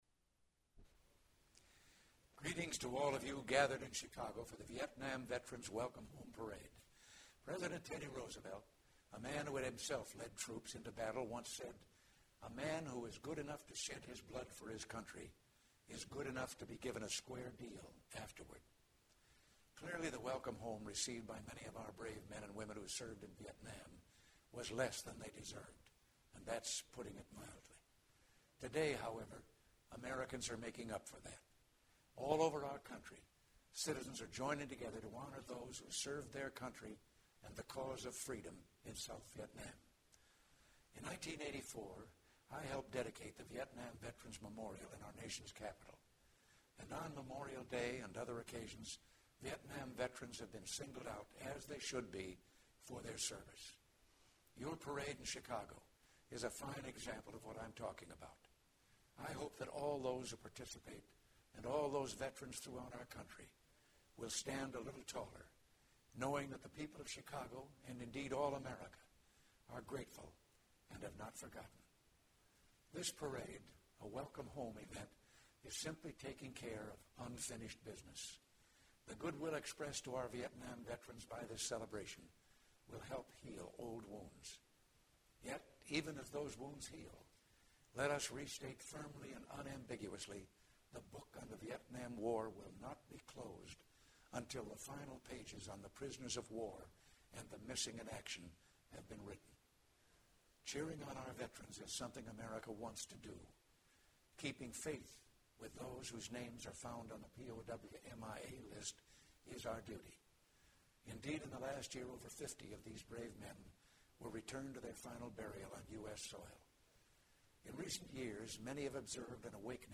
Remarks by President Ronald Reagan during video taping session for Chicago's Vietnam Veterans parade. Map Room.